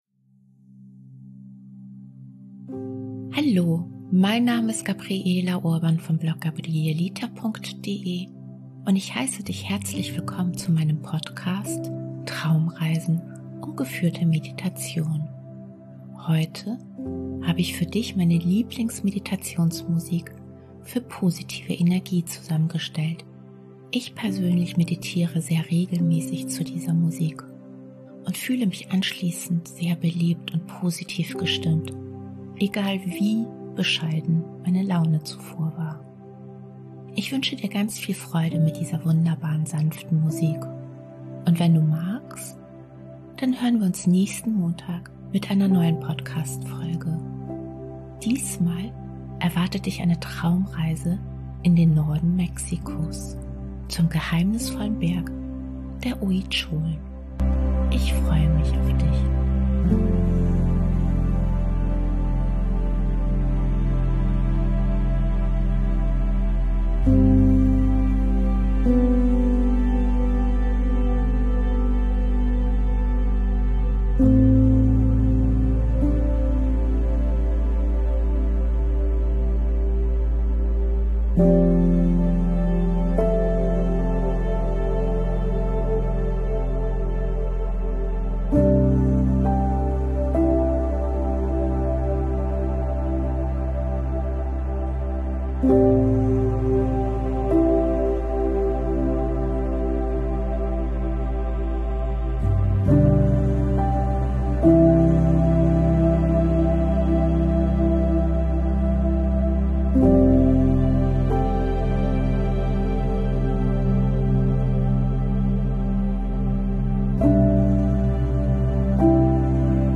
Ich wünsche dir ganz viel Freude mit dieser sanften Meditationsmusik positive Energie.